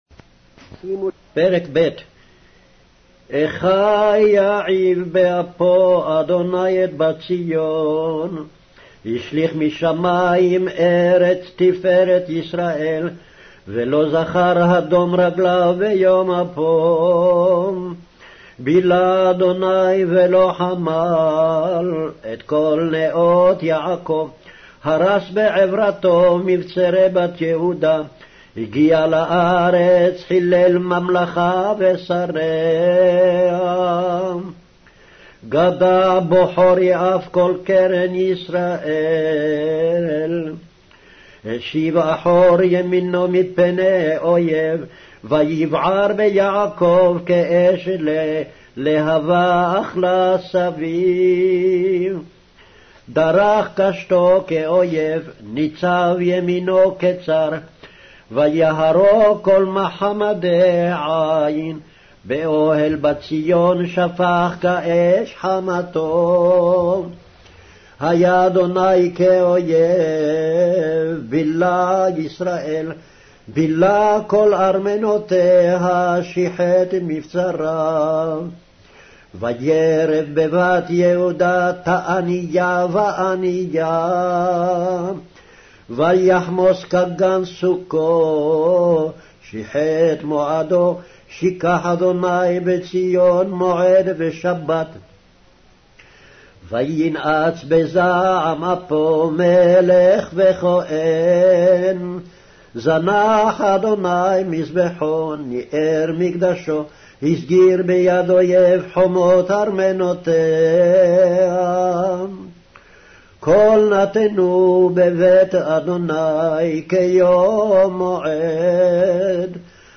Hebrew Audio Bible - Lamentations 4 in Irvgu bible version